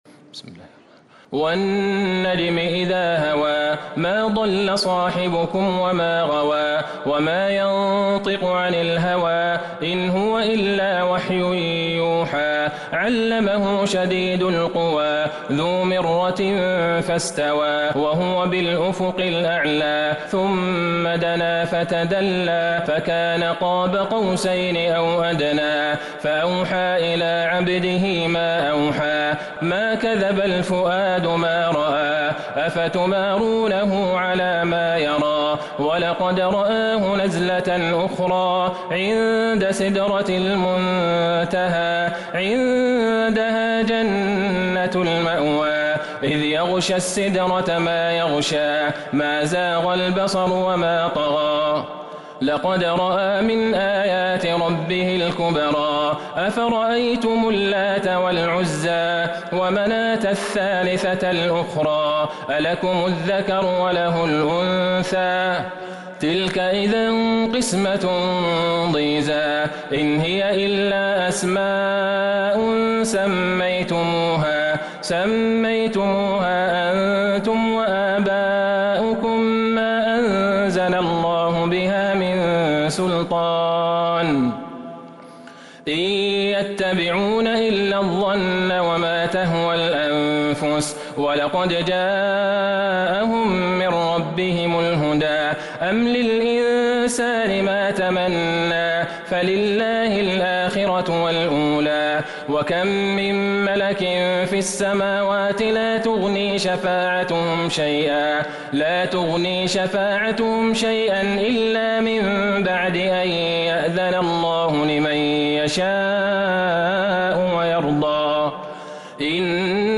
سورة النجم Surat An-Najm من تراويح المسجد النبوي 1442هـ > مصحف تراويح الحرم النبوي عام 1442هـ > المصحف - تلاوات الحرمين